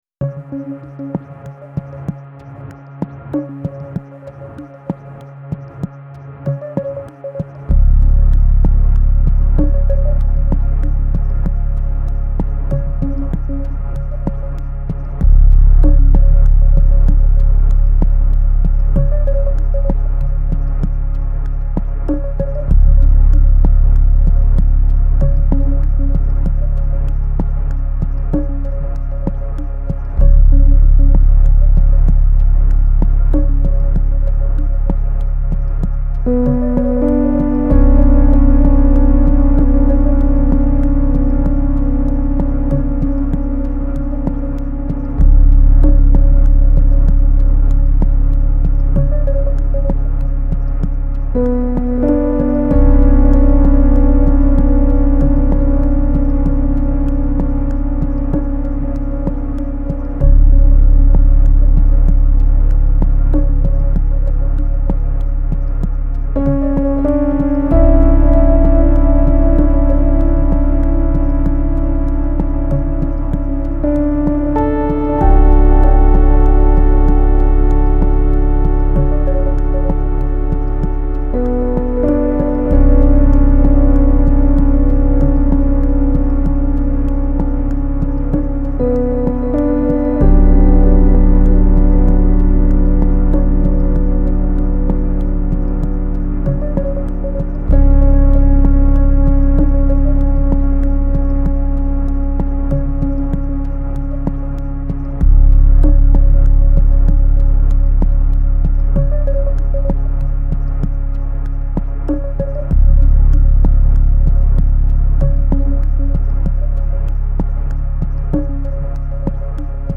Genre : Film Soundtracks